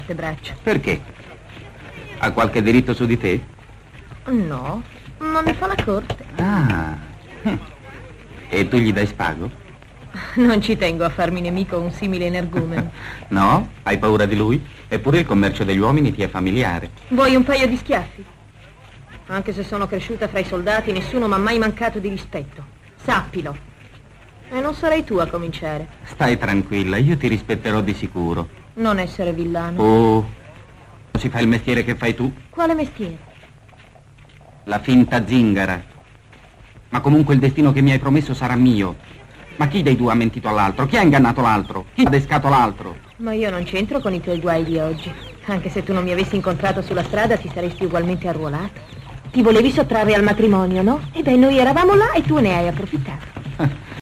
"Fanfan le Tulipe", in cui doppia Gina Lollobrigida.